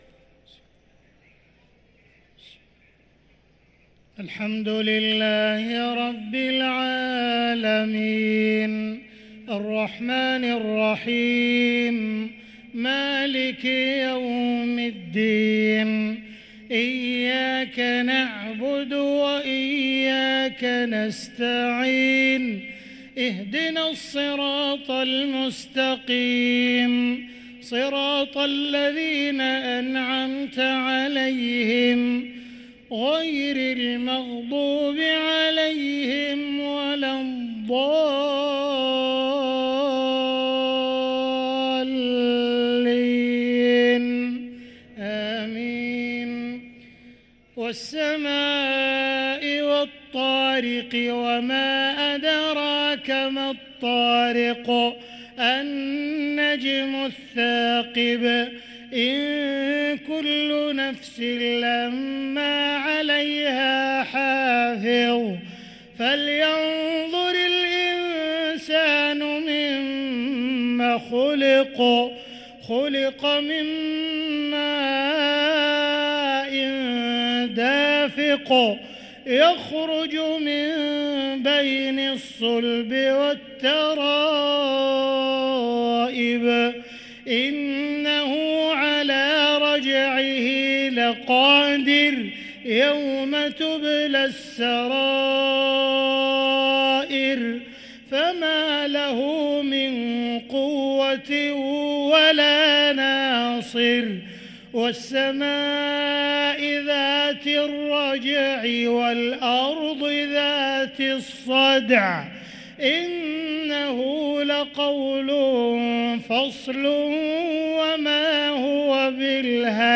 صلاة المغرب للقارئ عبدالرحمن السديس 10 رمضان 1444 هـ